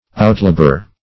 Outlabor \Out*la"bor\
outlabor.mp3